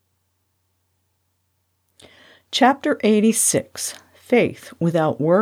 I am happy to report that I got the computer fixed and we did a little recording and checking until the end result both met the ACX robot check requirements and sounds like the earlier recordings, before we had our problem. Here’s a test file after I ran the tools on it.